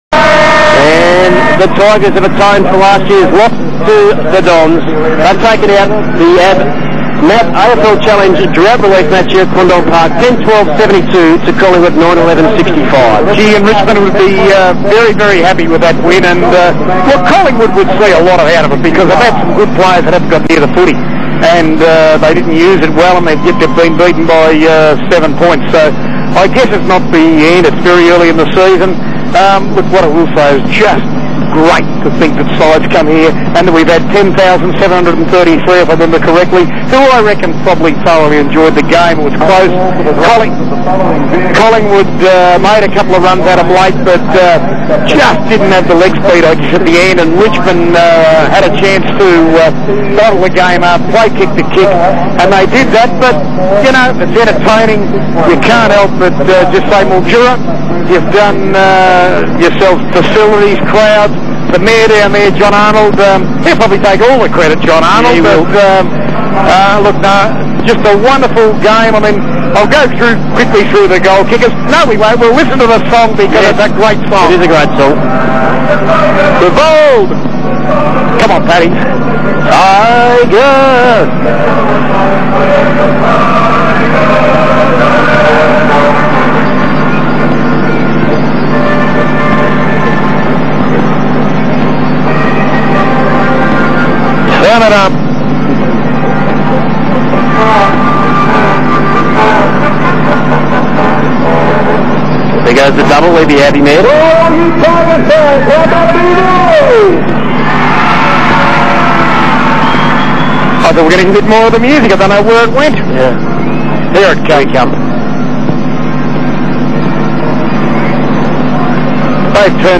HOT FM radio aftermatch audio - Tiges vs pies
Nothing better than hearing our theme song at the end of a game. Even the Pie fan commentators joined in  :wallywink.